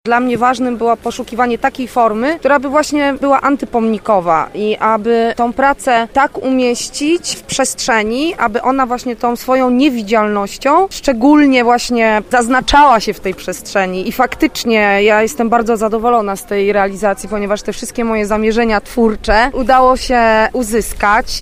Podczas wczorajszego spaceru twórcy opisywali słuchaczom swoje dzieła.
Mimo niesprzyjającej pogody mieszkańcy z zainteresowaniem słuchali wypowiedzi artystów.